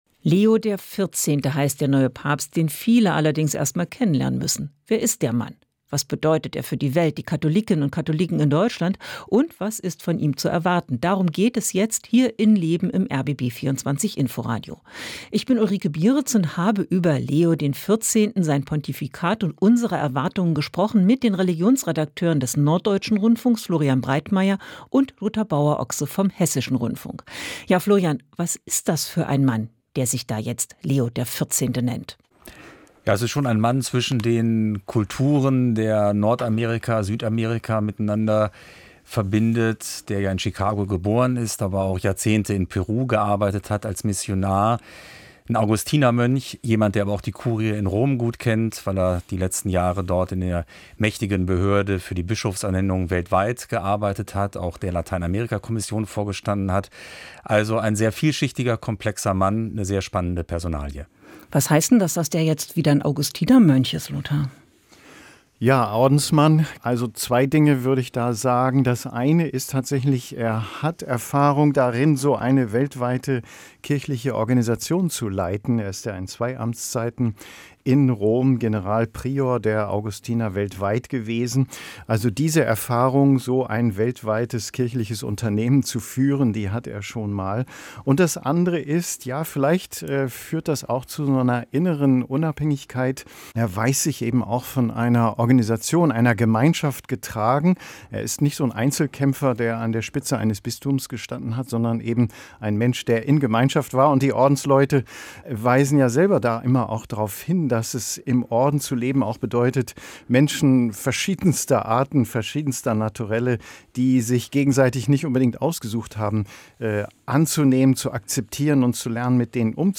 im Gespräch mit zwei Religionsjournalisten